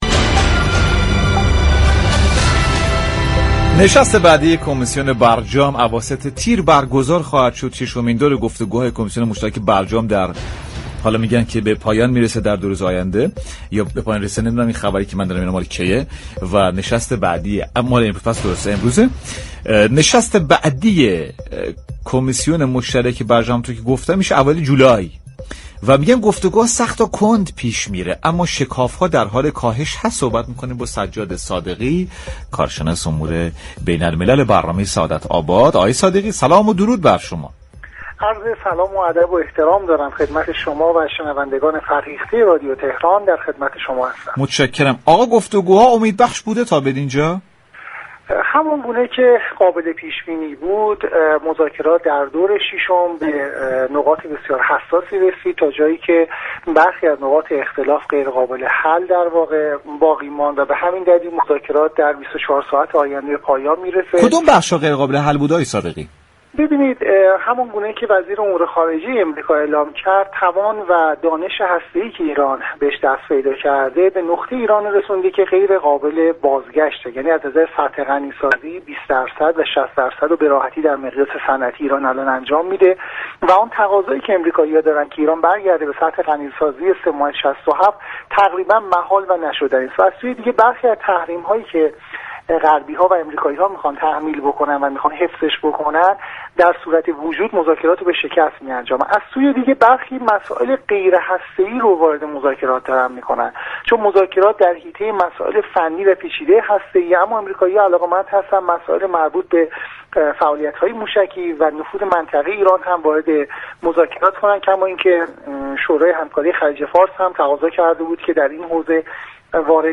كارشناس امور بین المللی در گفتگو با برنامه سعادت آباد رادیو تهران